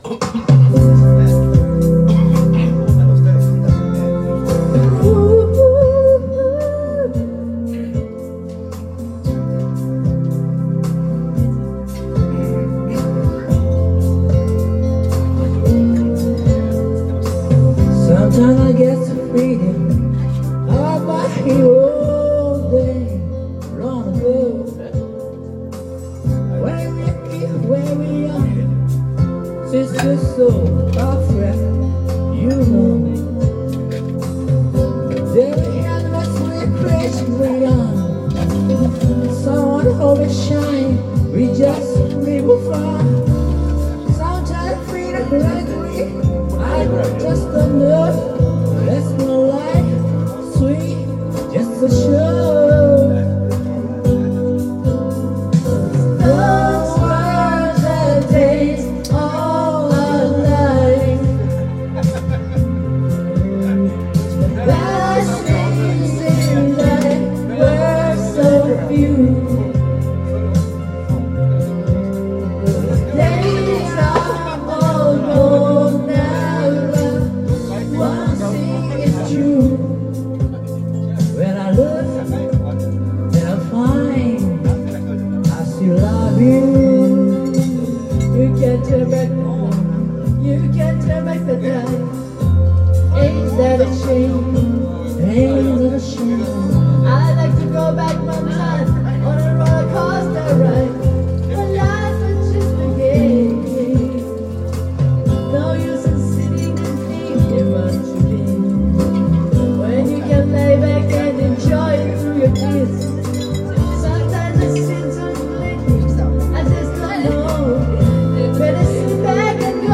Duet & Chorus Night Vol. 19 TURN TABLE